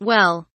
well kelimesinin anlamı, resimli anlatımı ve sesli okunuşu